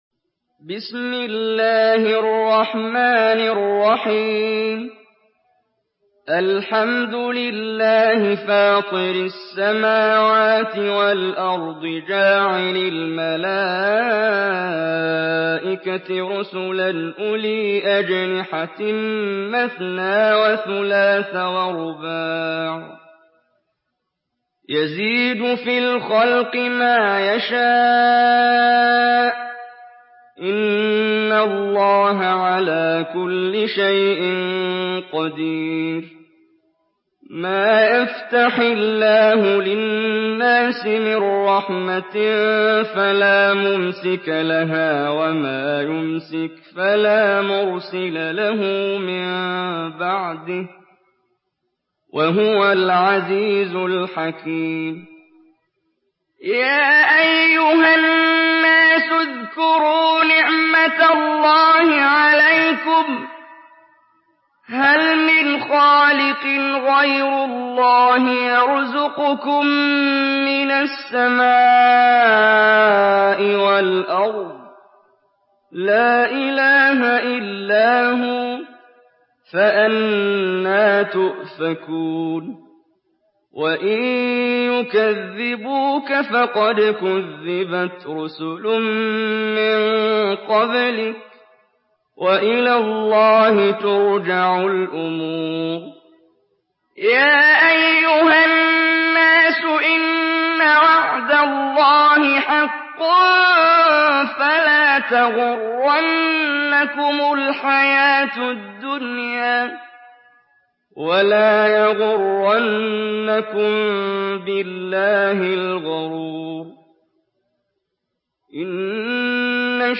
Surah ফাতের MP3 by Muhammad Jibreel in Hafs An Asim narration.
Murattal Hafs An Asim